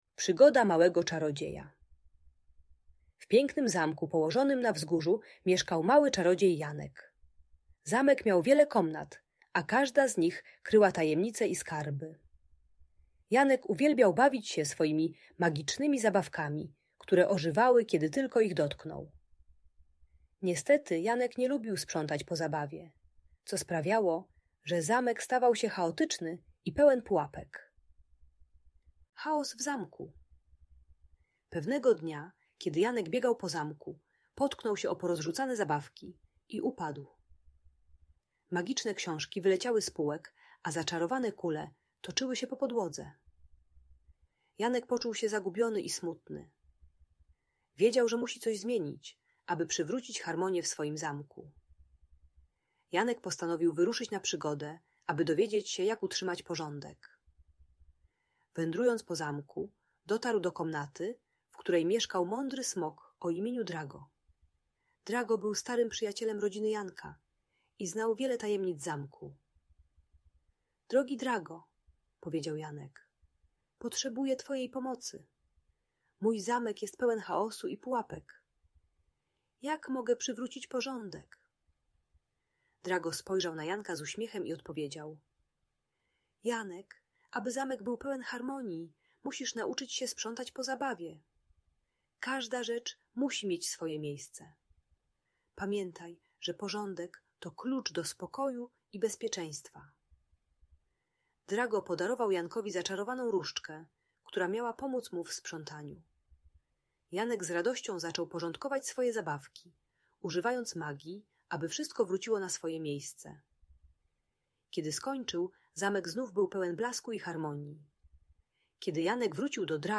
Przygoda Małego Czarodzieja - Niepokojące zachowania | Audiobajka